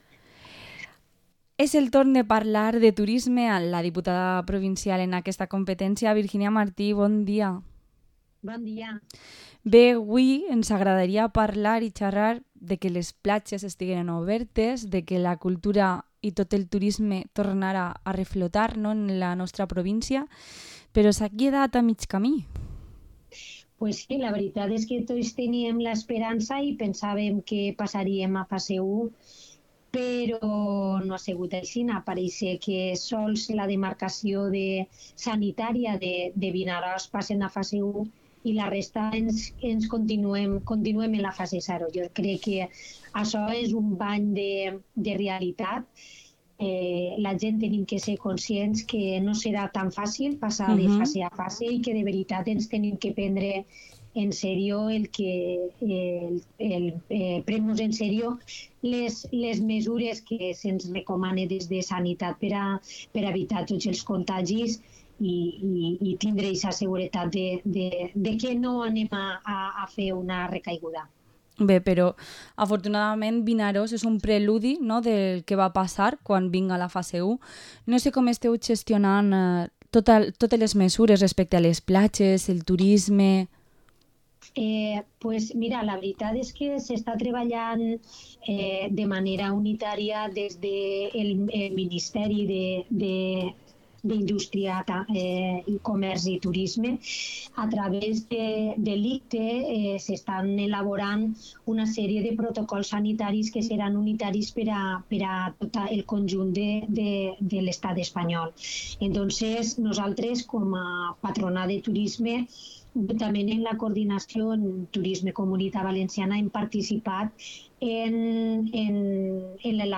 Entrevista a la diputada provincial de Turismo, Virginia Martín